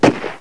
gun.wav